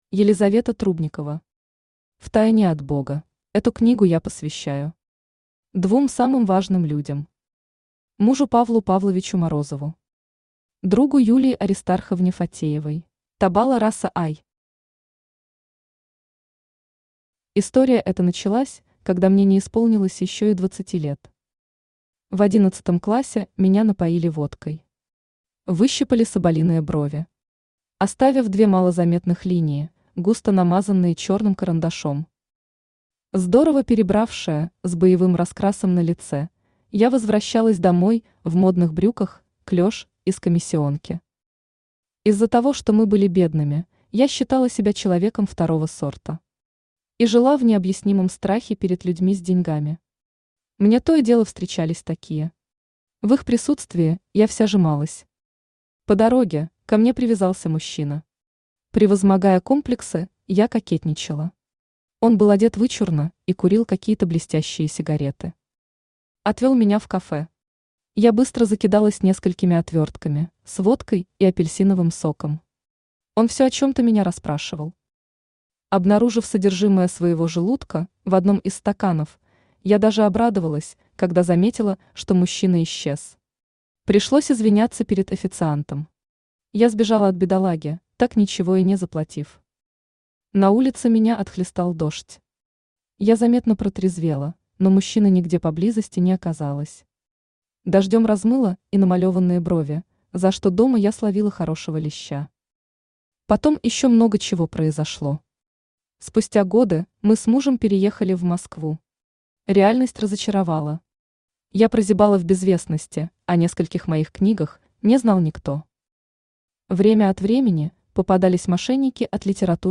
Аудиокнига Втайне от Бога | Библиотека аудиокниг
Aудиокнига Втайне от Бога Автор Елизавета Валерьевна Трубникова Читает аудиокнигу Авточтец ЛитРес.